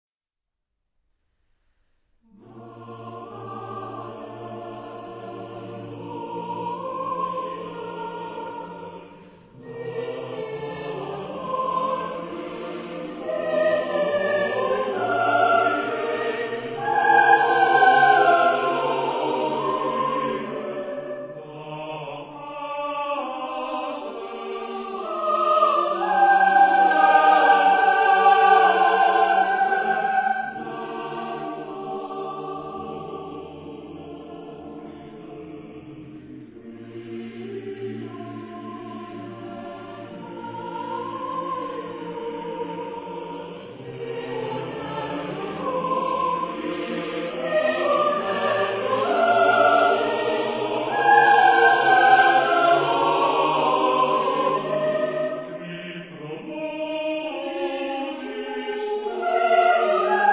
Género/Estilo/Forma: Sagrado ; Motete
Carácter de la pieza : andante
Tipo de formación coral: SATBarB  (5 voces Coro mixto )
Tonalidad : libre